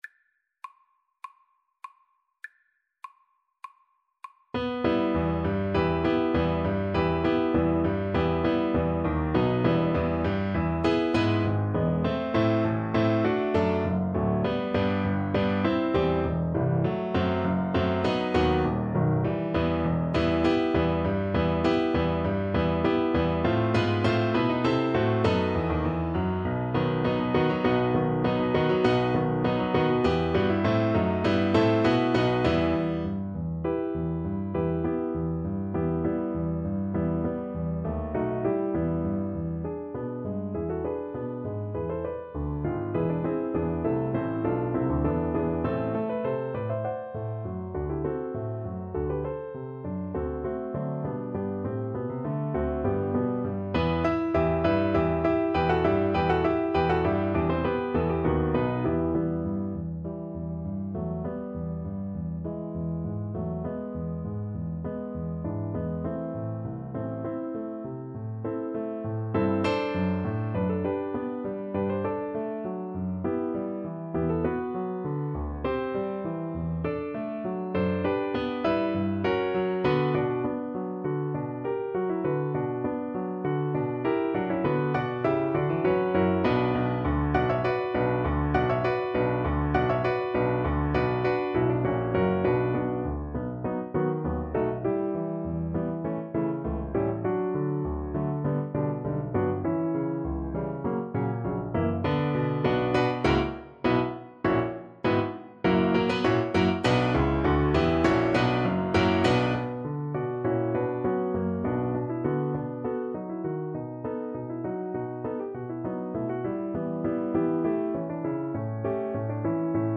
Classical Vivaldi, Antonio Concerto in A Minor Op. 3, No. 6 RV 356 First Movement Cello version
Play (or use space bar on your keyboard) Pause Music Playalong - Piano Accompaniment Playalong Band Accompaniment not yet available transpose reset tempo print settings full screen
Cello
~ = 100 Allegro (View more music marked Allegro)
E minor (Sounding Pitch) (View more E minor Music for Cello )
4/4 (View more 4/4 Music)
Classical (View more Classical Cello Music)